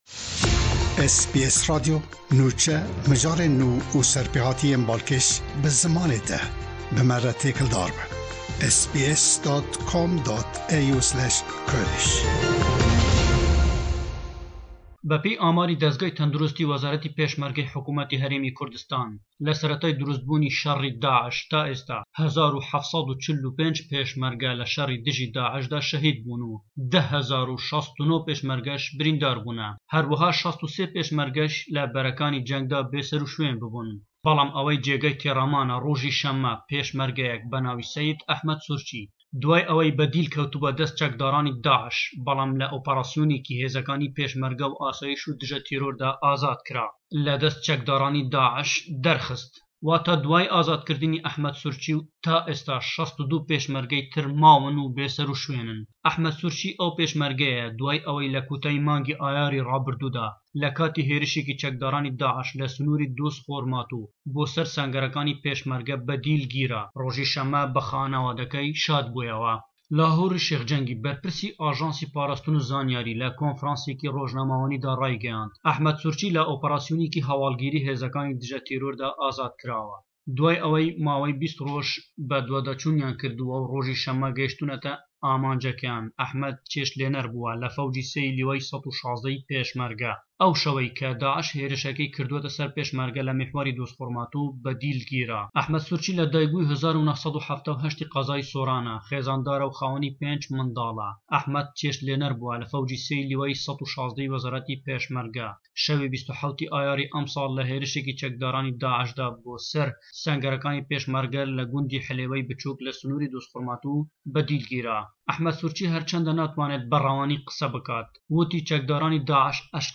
Raporta ji Hewlêr, rizgarkirina pêshmergeyekî ji chetayên Daish